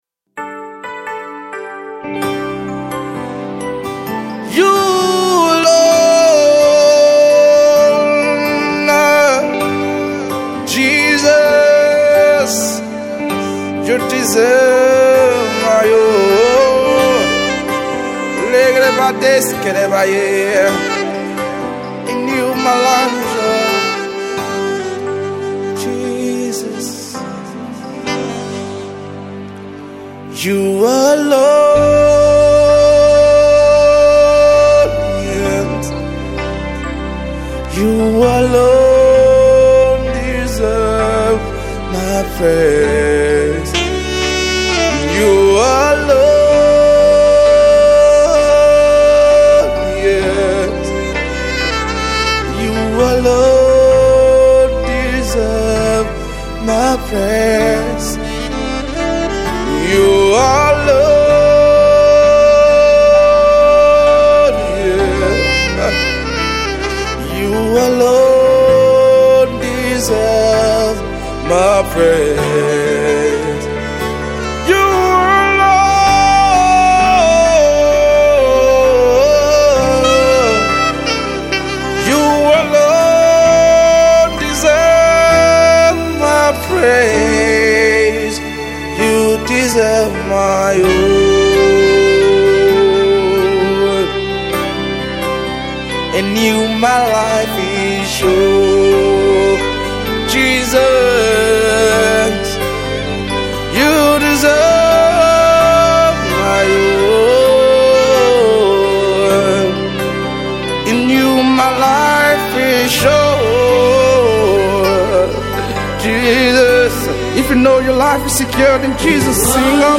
it’s powerful and full of the Spirit.